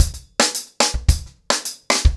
TimeToRun-110BPM.41.wav